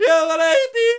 JODLER     4.wav